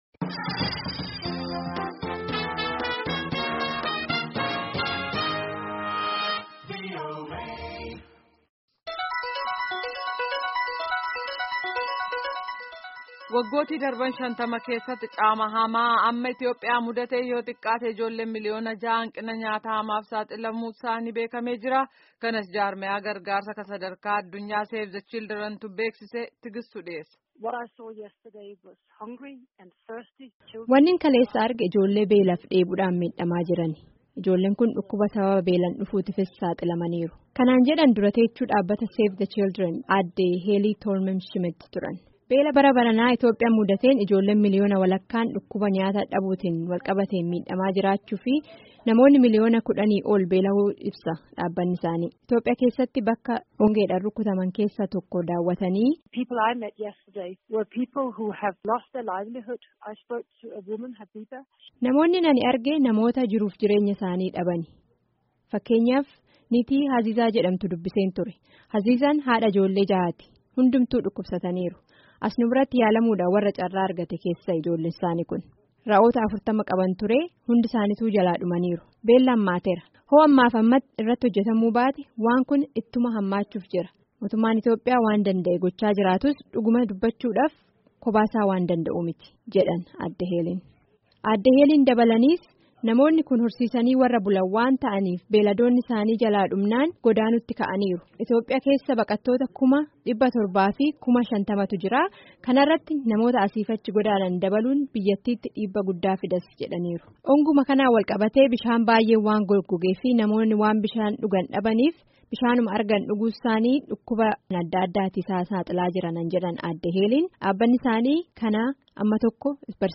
Gabaasa sagalee.